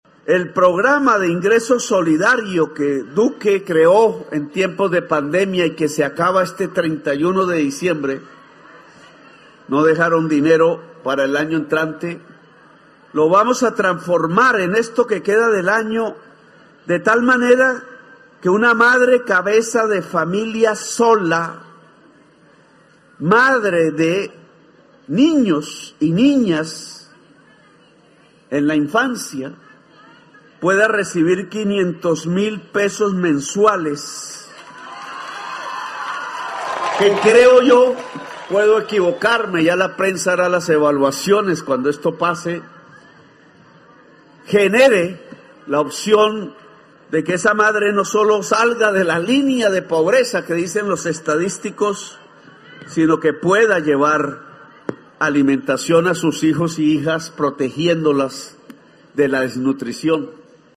Así lo anunció el Jefe de Estado en el municipio de Bosconia (Cesar), donde se instaló el Puesto de Mando Unificado (PMU) para enfrentar las emergencias sufridas en la región y donde el Jefe de Estado escuchó a la comunidad.